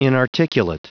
Prononciation du mot inarticulate en anglais (fichier audio)
Prononciation du mot : inarticulate